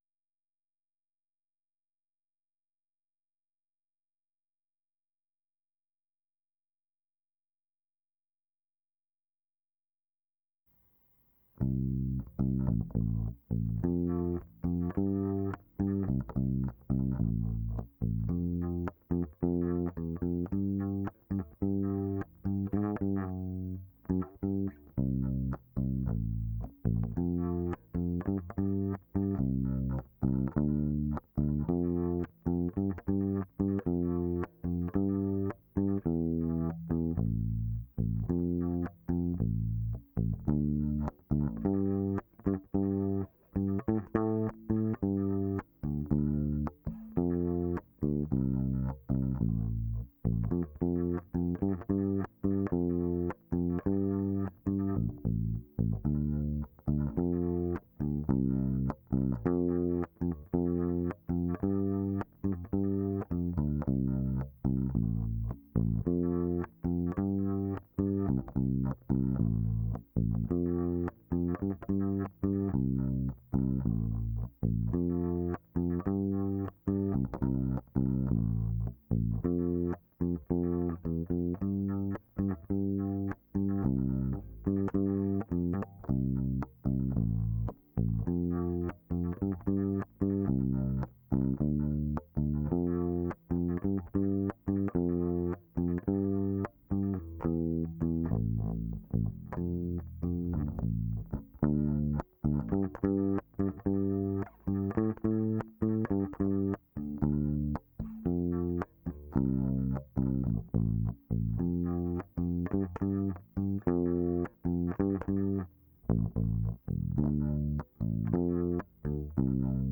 basstake1.wav